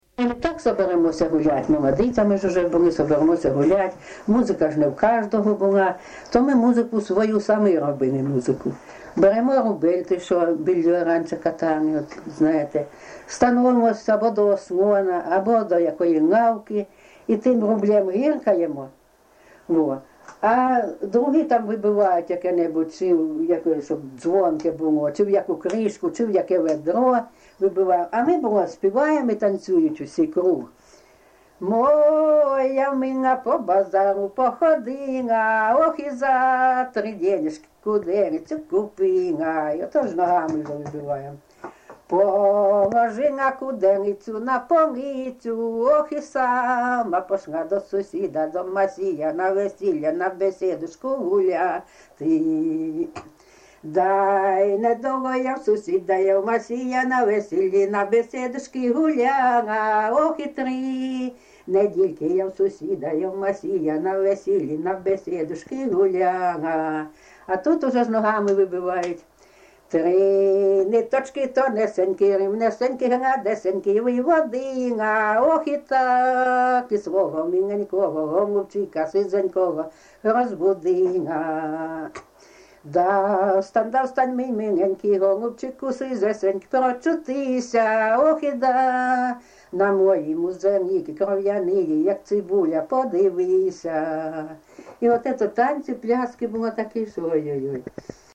ЖанрЖартівливі
Місце записус. Курахівка, Покровський район, Донецька обл., Україна, Слобожанщина